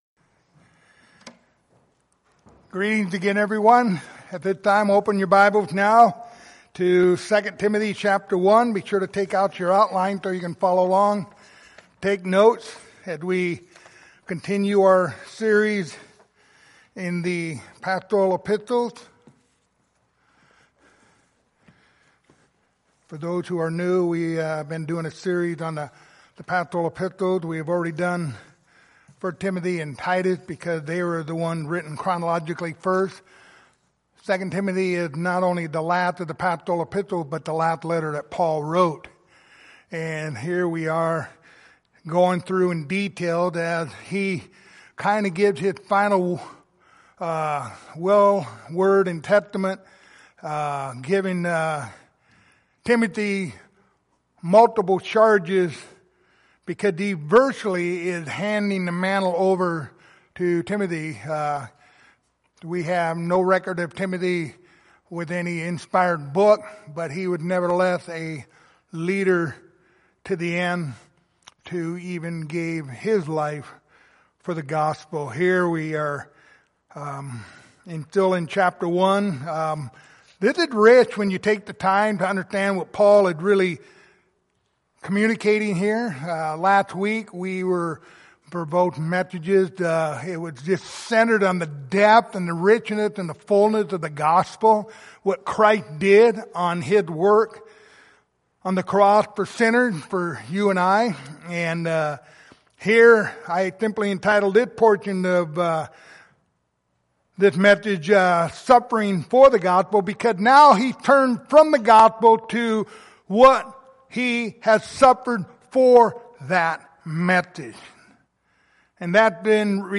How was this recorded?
Passage: 2 Timothy 1:11-12 Service Type: Sunday Morning Topics